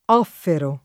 DOP: Dizionario di Ortografia e Pronunzia della lingua italiana
offrire v.; offro [0ffro] — pass. rem. offrii [offr&-i] (lett. offersi [off$rSi]); part. pres. offerente [offer$nte]; part. pass. offerto [off$rto] — antiq. offerire [offer&re] (e nell’uso più ant. anche offerere [offer%re]): offero [